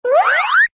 toonbldg_grow.ogg